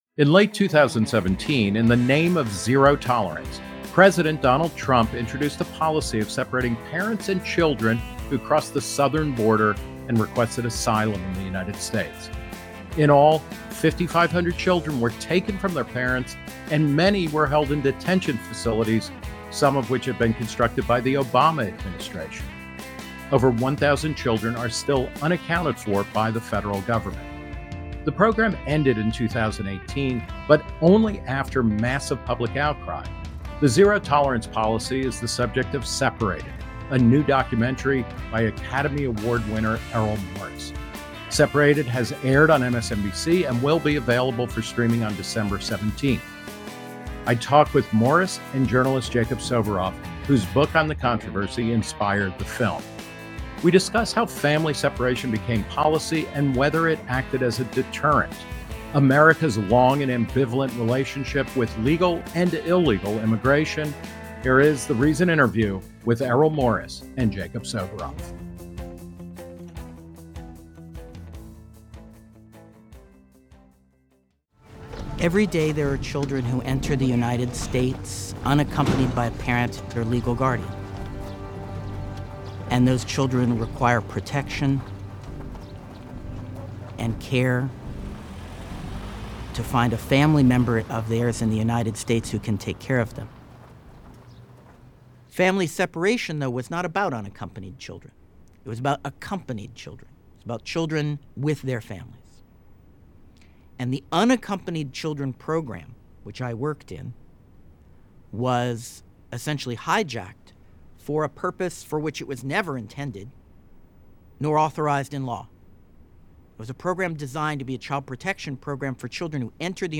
Errol Morris and Jacob Soboroff discuss their new documentary about the family separation policy implemented during Trump’s first term.
Reason 's Nick Gillespie talks with Morris and journalist Jacob Soboroff, whose book on the controversy inspired the film.